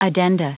Prononciation du mot addenda en anglais (fichier audio)
Prononciation du mot : addenda